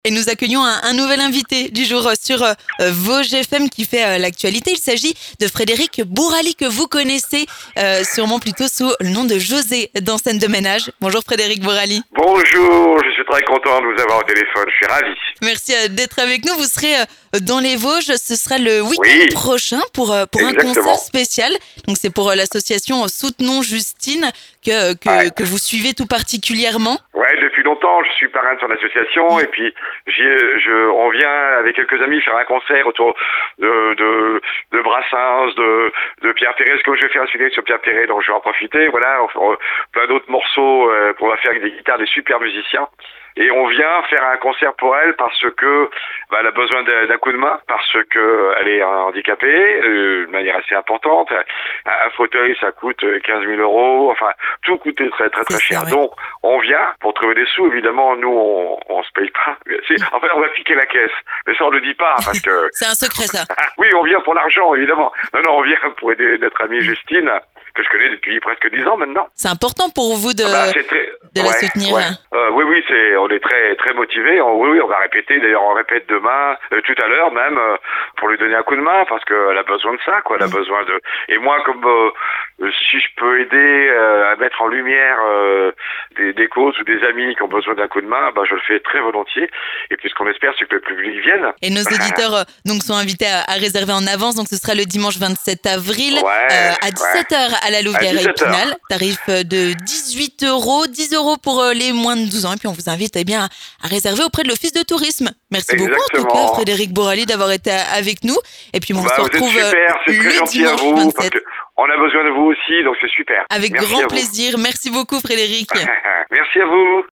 L'invité du jour
Frédéric Bouraly, José dans "Scènes de Ménage", est notre invité du jour sur Vosges FM. Le dimanche 27 avril prochain, il sera présent à Epinal pour un concert solidaire avec le Donzella Trio.